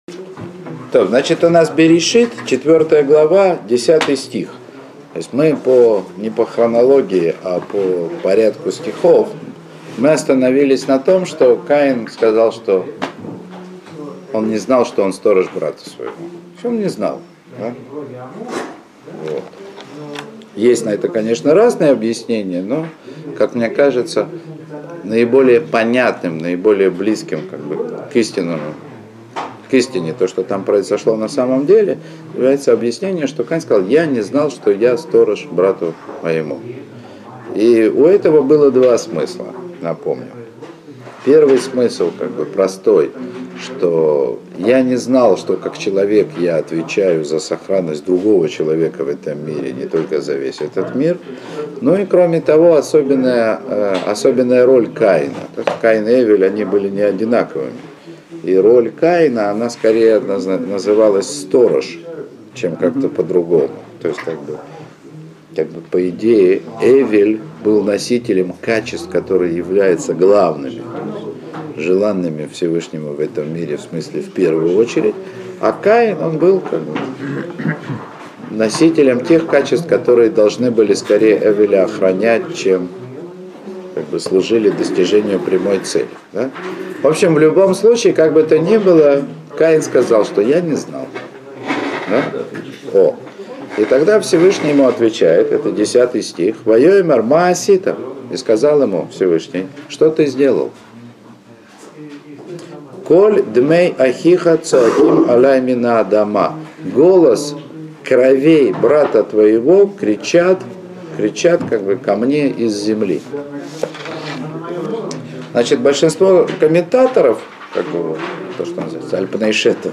Уроки по книге Берейшит.